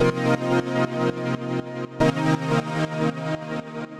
Index of /musicradar/sidechained-samples/120bpm
GnS_Pad-dbx1:8_120-C.wav